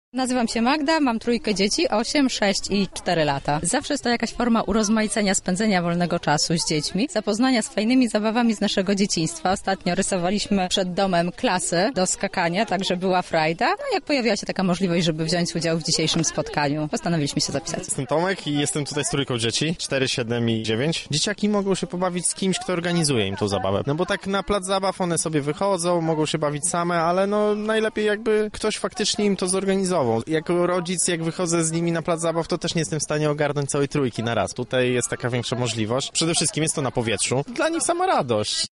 Kolejne spotkanie z grami plenerowymi odbyło się na osiedlu Słowackiego, na miejscu był nasz reporter: